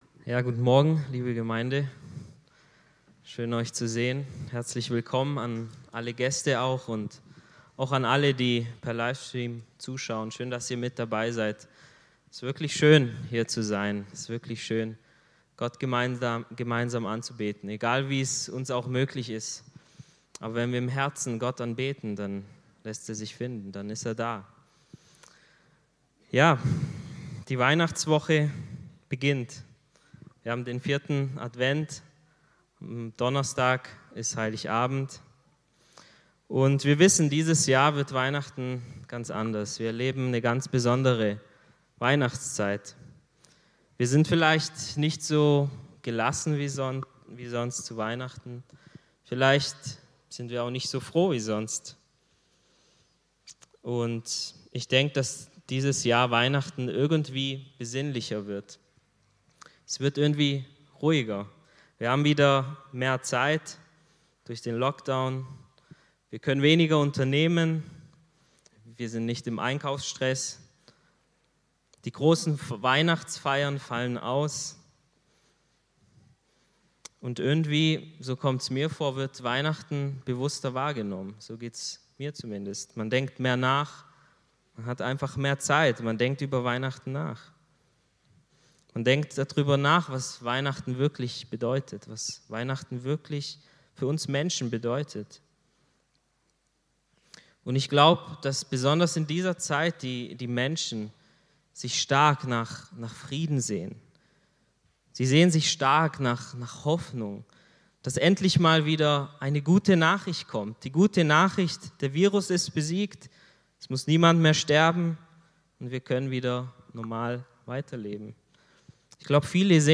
Predigt
im Christlichen Zentrum Villingen-Schwenningen.